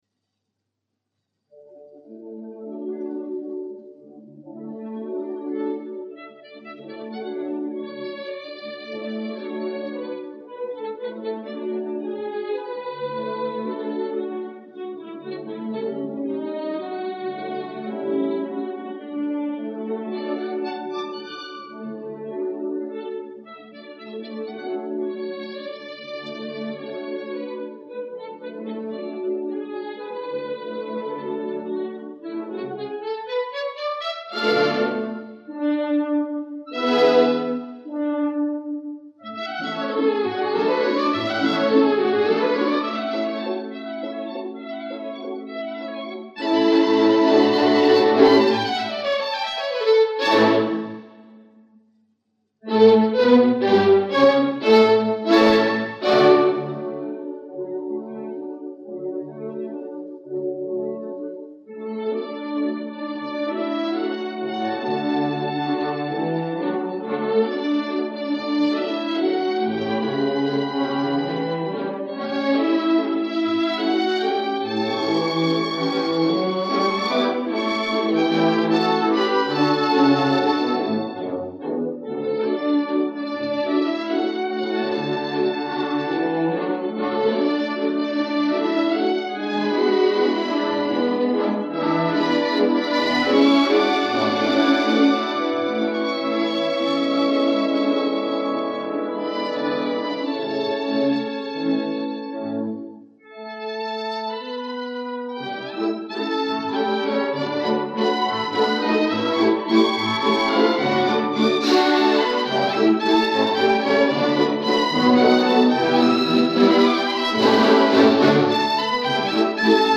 Миньон 1961 года.